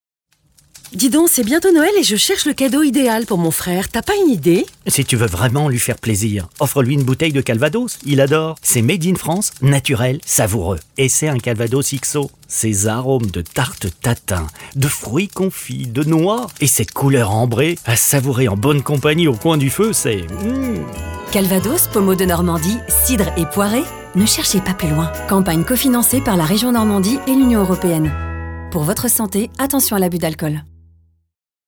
Une campagne radio pour soutenir la filière
IDAC SPOT D-LE CADEAU CALVADOS.mp3